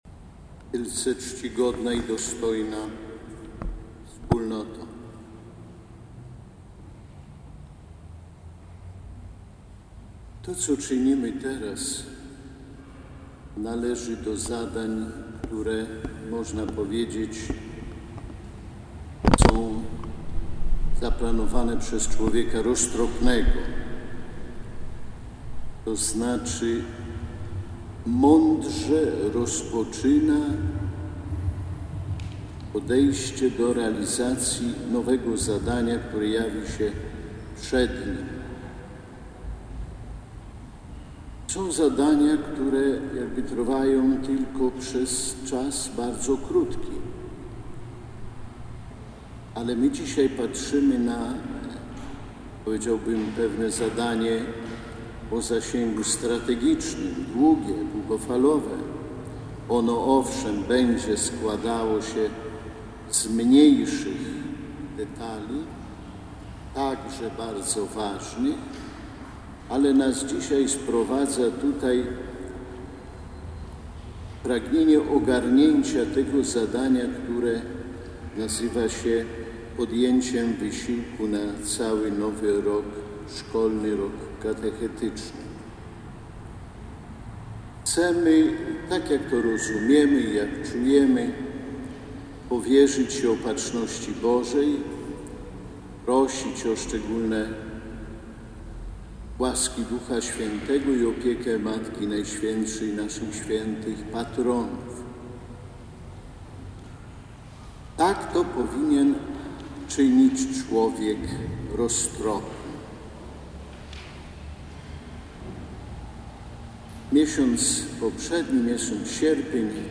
Rozpoczęły się odprawy – nauczyciele religii spotkali się w katedrze warszawsko-praskiej na Mszy świętej pod przewodnictwem biskupa Romualda Kamińskiego.